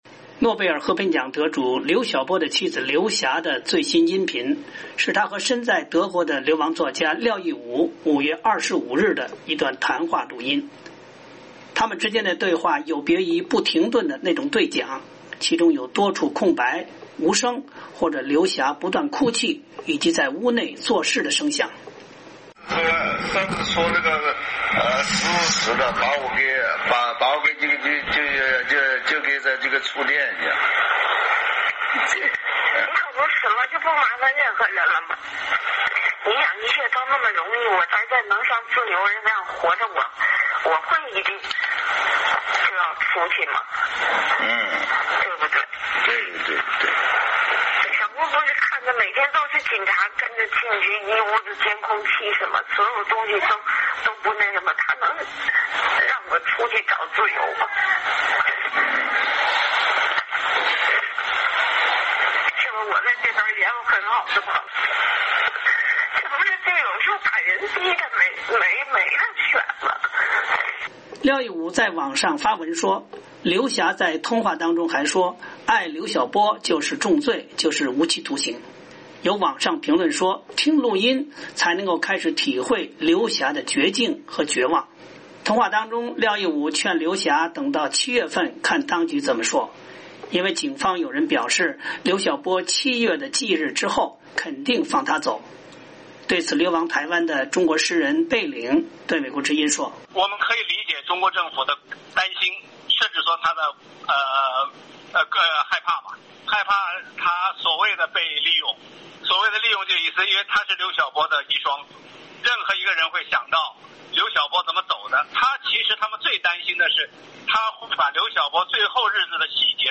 他们之间的对话有别于不停顿的那种对讲，其中有多处空白、无声、或者刘霞不断抽泣，以及她在屋内做事的声响。